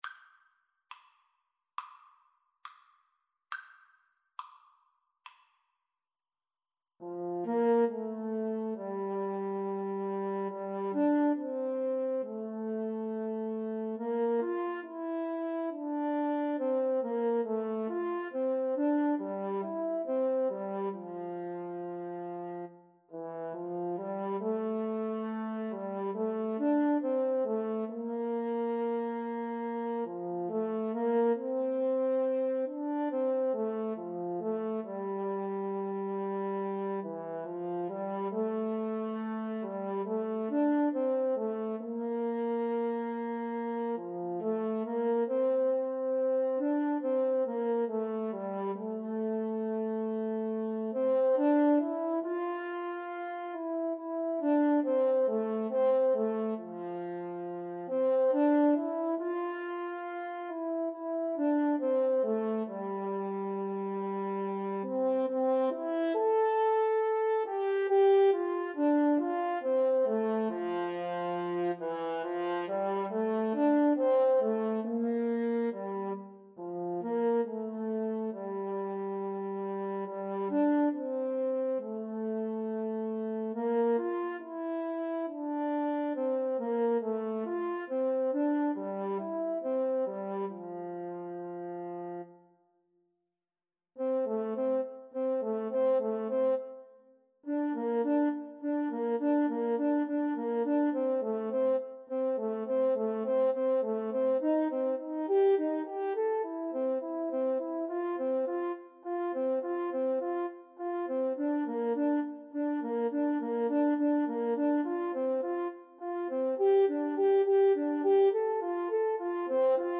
4/4 (View more 4/4 Music)
Espressivo = c. 69
Classical (View more Classical French Horn Duet Music)